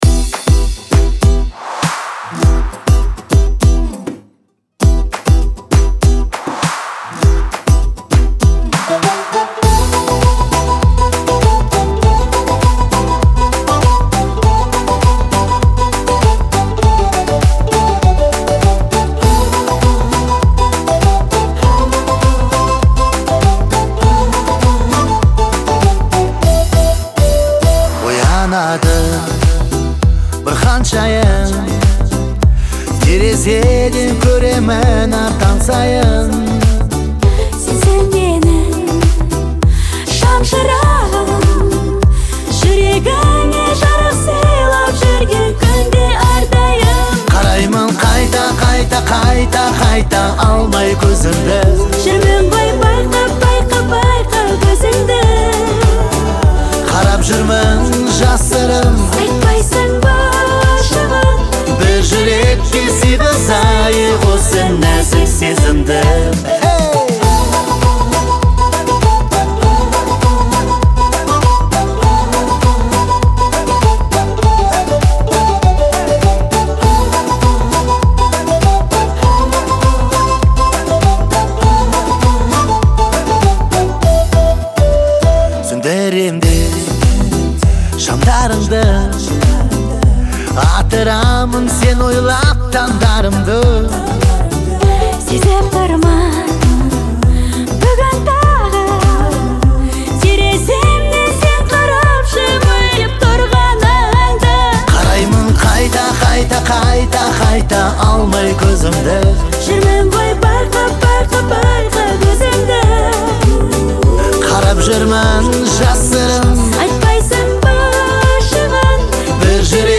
это романтическая песня в жанре поп